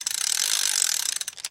Звуки удочки
Чуть-чуть подтянули